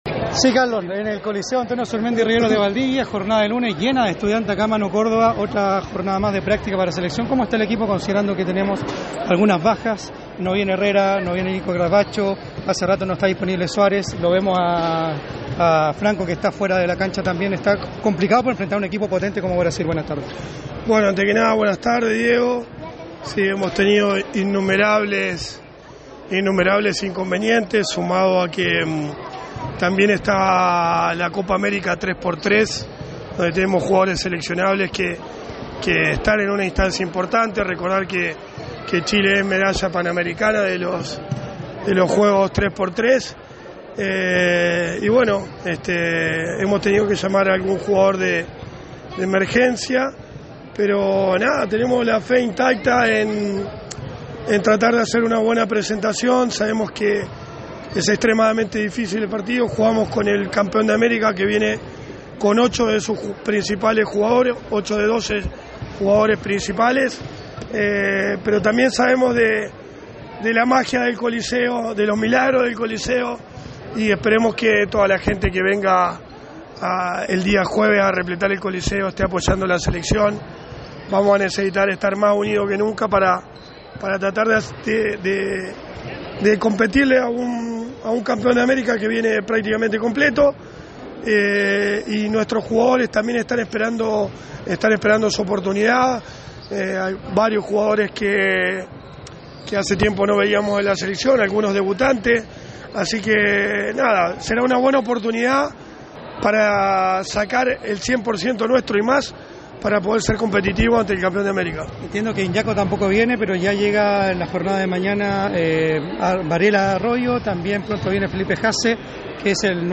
En conversación con ADN TOP